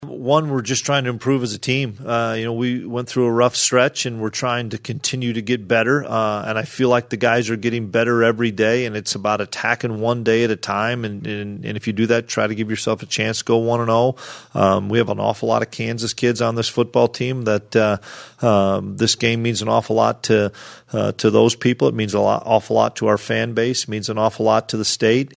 Kansas State Coach Chris Klieman says it also is about getting better for the Wildcats.